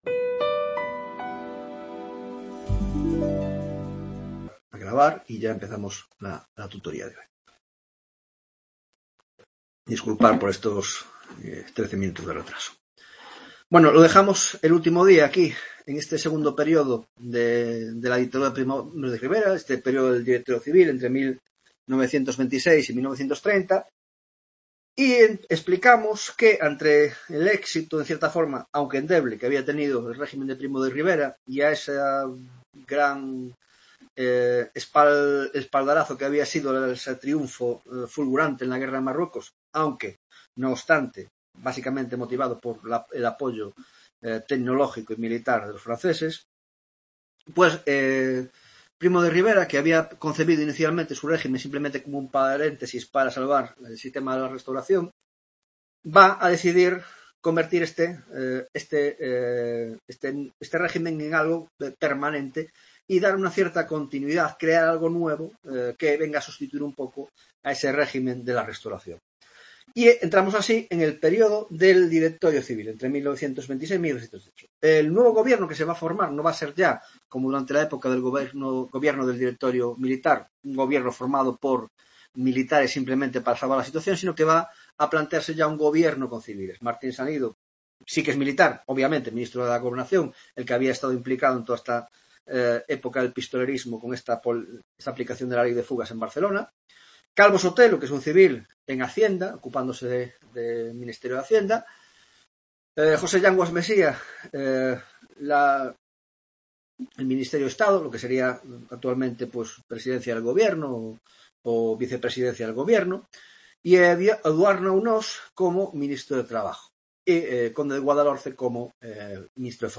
15ª tutoria de Historia Contemporánea